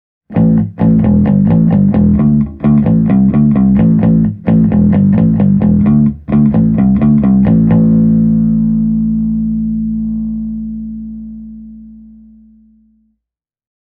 Isobaarinen Smart Power -kaappi kuulostaa ilahduttavan isolta ja lihaksikkaalta, ja myös potkua löyttyy mielin määrin.
Äänitin seuraavat esimerkipätkät japanilaisella Squier Jazz -bassolla, sekä passivisella MM-humbuckerilla varustetulla P-Man-bassollani:
Jazz Bass – plektralla
jazz-bass-e28093-pleck.mp3